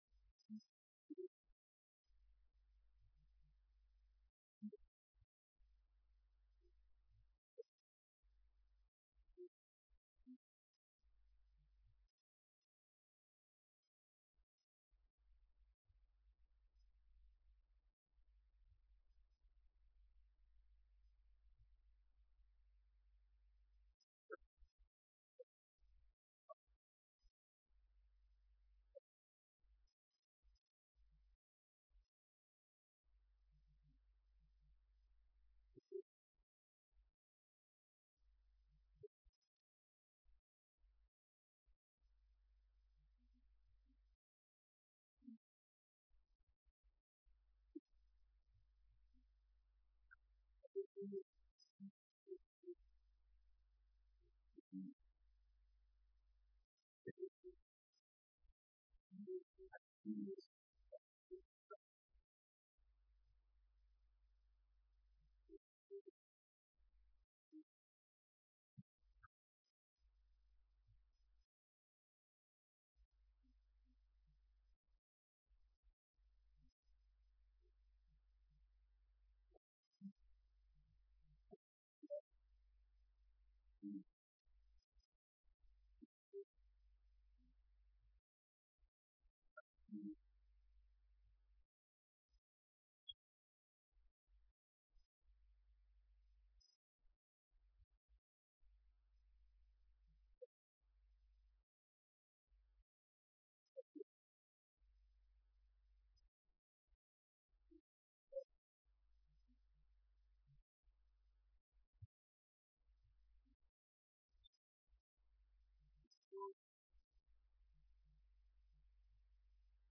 بیانات در دیدار مسوولین و سفرای کشورهای اسلامی